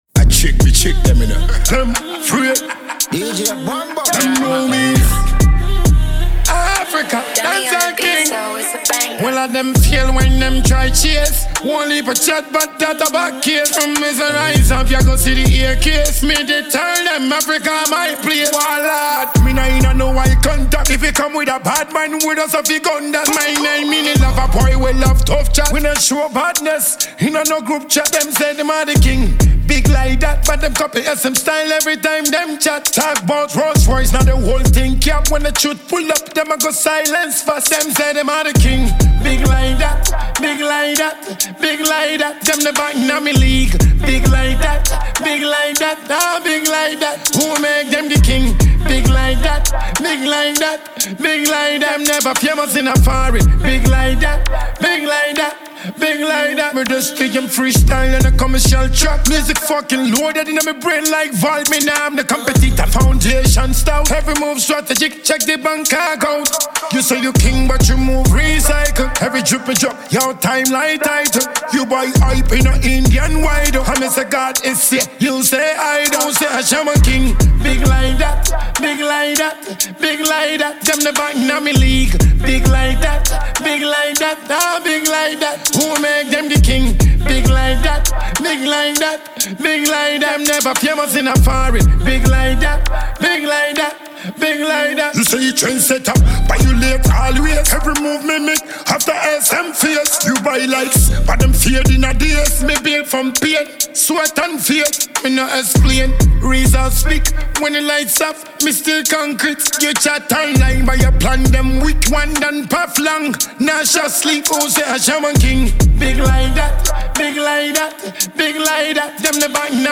the African dancehall king and multiple award-winning act
Ghana Music